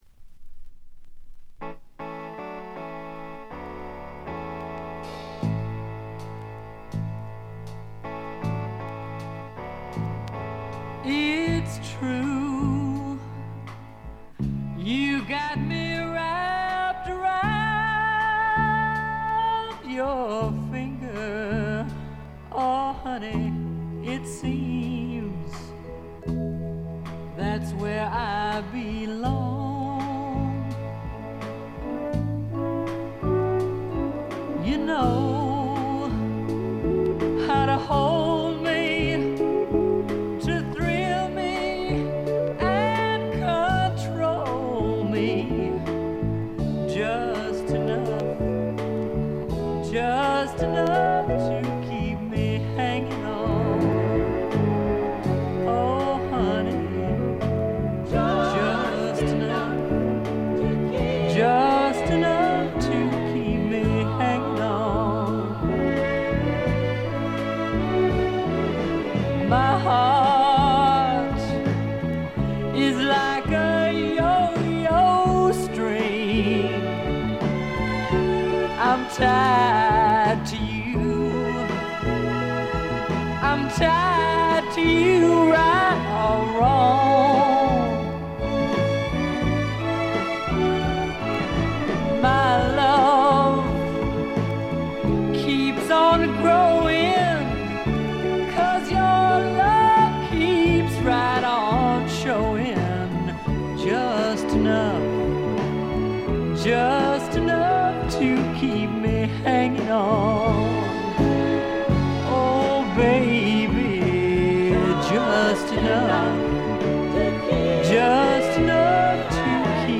ほとんどノイズ感無し。
試聴曲は現品からの取り込み音源です。
Recorded At - Muscle Shoals Sound Studios